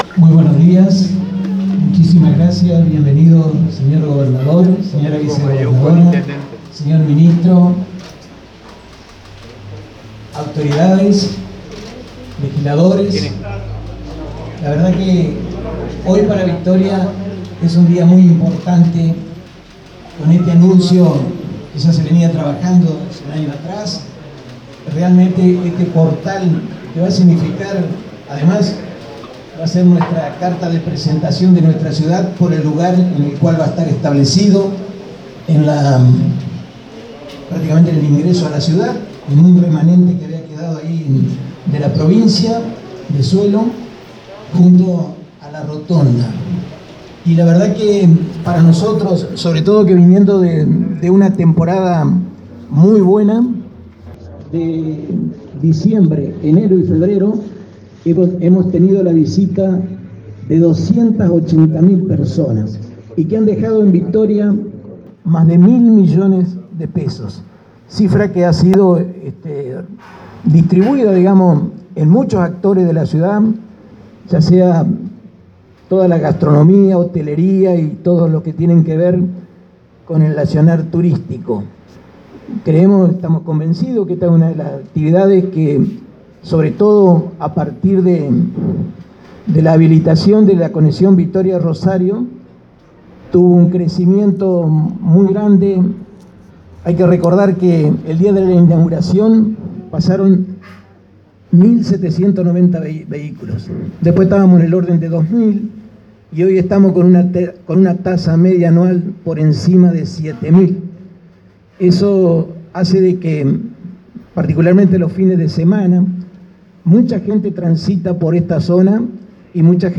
El acto se llevó a cabo en el salón del Tiro Federal, y en la oportunidad hicieron uso de la palabra Maiocco, Stratta y Bordet, quienes destacaron la importancia del trabajo conjunto de los gobiernos local, provincial y nacional en el desarrollo de obras que siendo necesarias exceden la capacidad de inversión de los municipios.
Intendente Domingo Maiocco
MAIOCCO-DISCURSO-1.wav